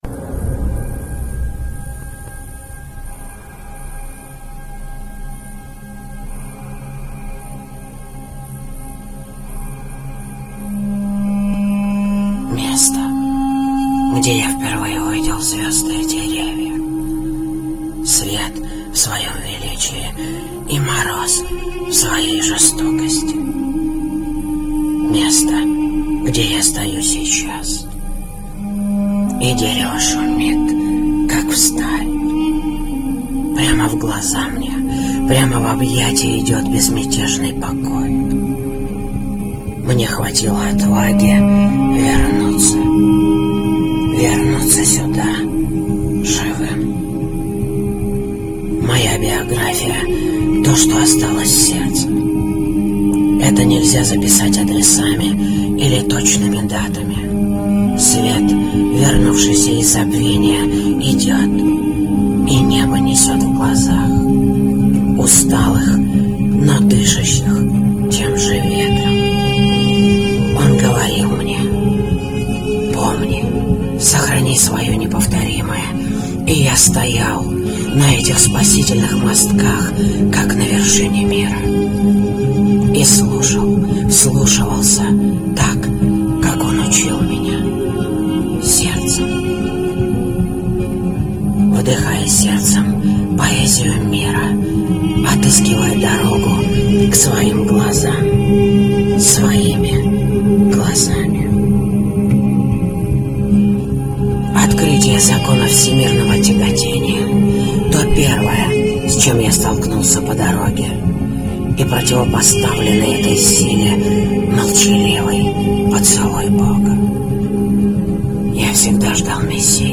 Мелодекламация Аудиопоэзия